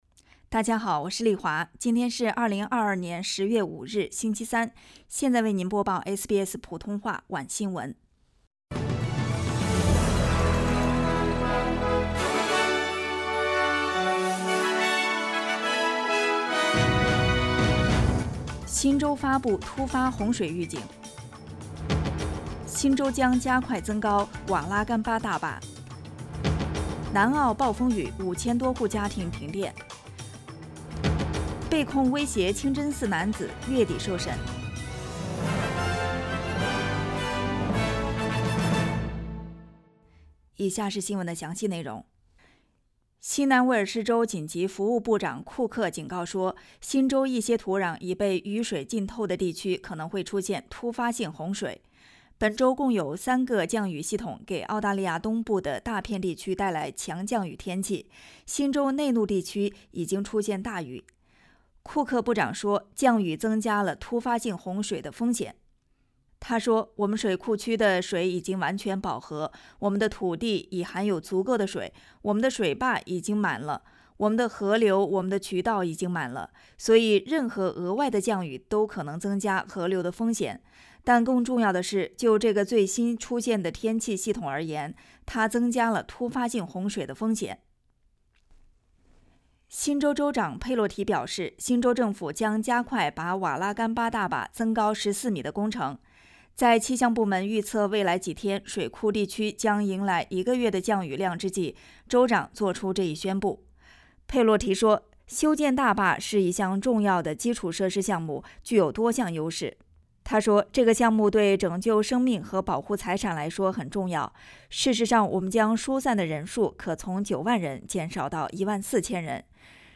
SBS晚新闻（2022年10月5日）